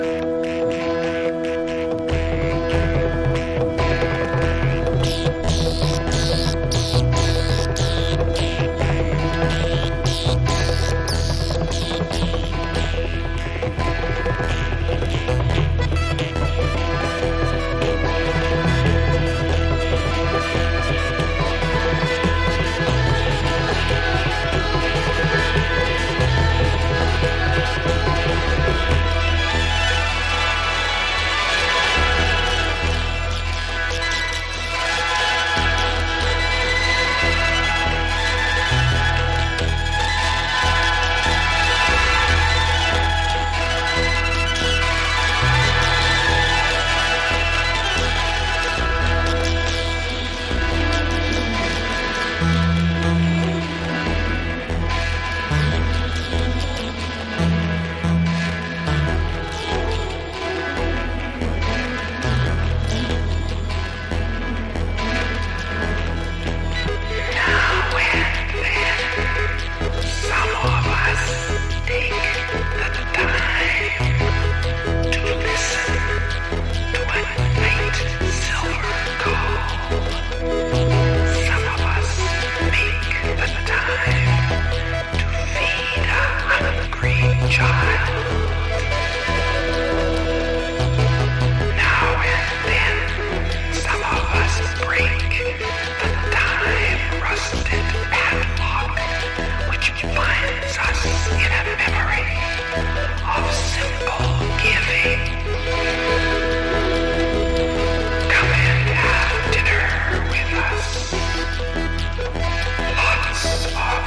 電子音楽コラージュ/ミュージック・コンクレート傑作をたっぷり収録！